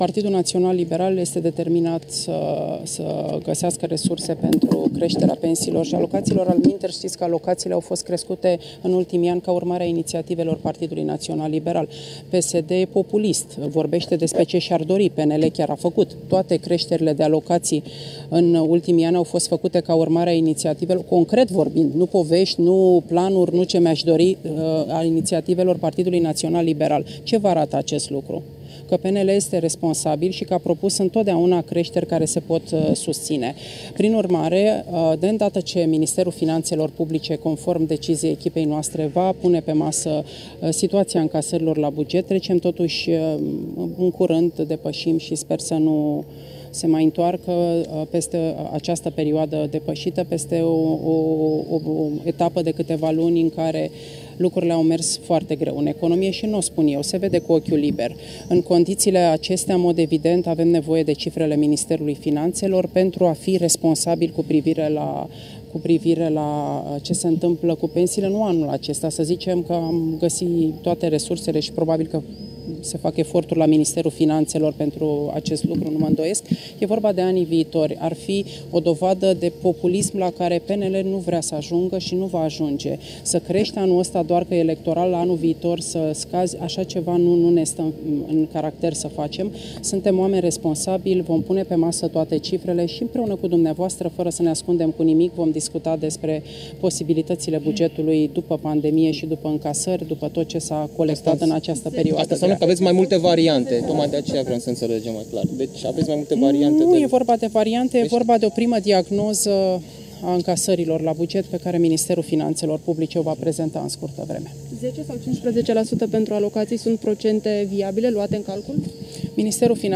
“PNL este determinat să găsească resurse pentru creşterea pensiilor şi a alocaţiilor, altminteri ştiţi că alocaţiile au fost crescute în ultimii ani ca urmare a iniţiativei PNL. PSD este populist. Vorbeşte despre ce şi-ar dori. PNL chiar a făcut. Toate creşterile de alocaţii, în ultimii ani, au fost făcute ca urmare a iniţiativelor PNL. (…) Avem nevoie de cifrele Ministerului Finanţelor pentru a fi responsabili cu privire la ce se întâmplă cu pensiile”, a afirmat, într-o conferinţă de presă, Violeta Alexandru.